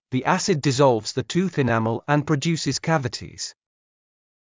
ｼﾞ ｱｼｯﾄﾞ ﾃﾞｨｿﾞｰﾙﾌﾞ ｻﾞ ﾄｩｰｽ ｴﾅﾓｳ ｴﾝﾄﾞ ﾌﾟﾛﾃﾞｭｰｼｰｽﾞ ｷｬｳﾞｨﾃｨｰｽﾞ